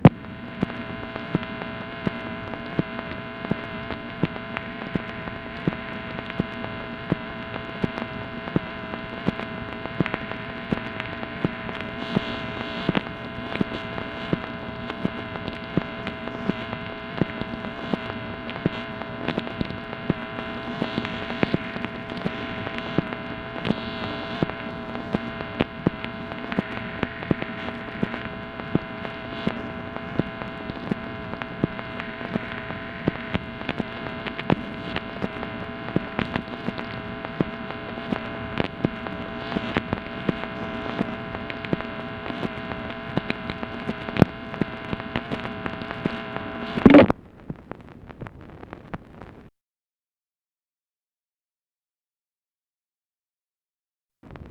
MACHINE NOISE, August 30, 1968
Secret White House Tapes | Lyndon B. Johnson Presidency